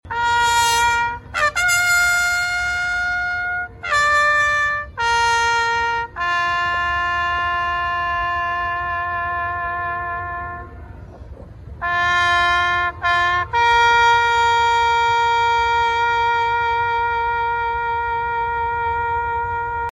Taps